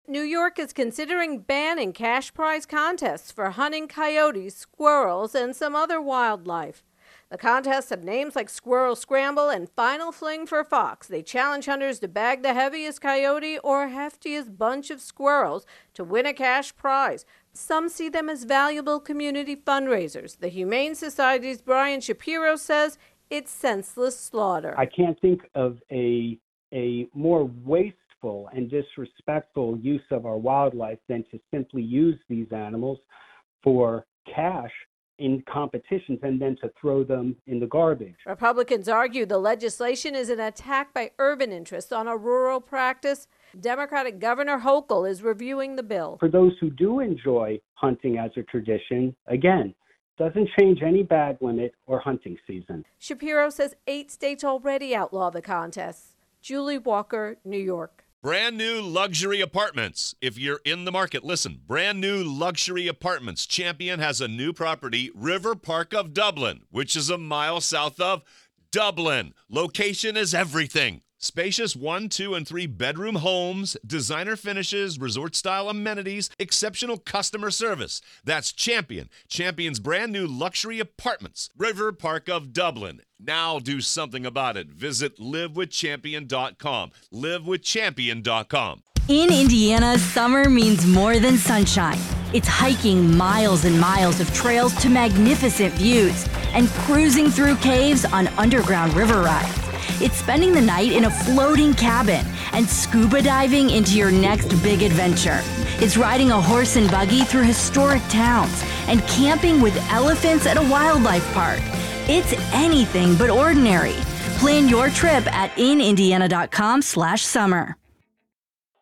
reports on Hunting Contest Ban